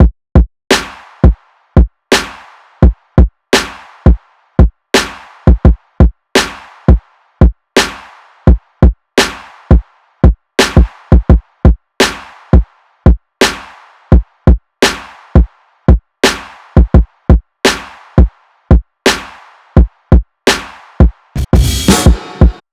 Drums.wav